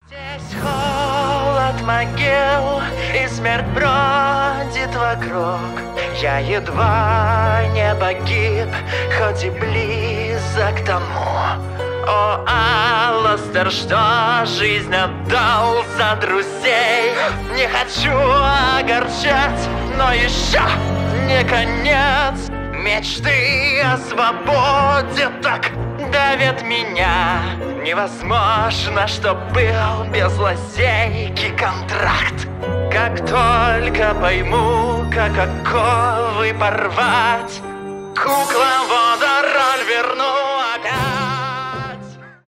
cover , мужской голос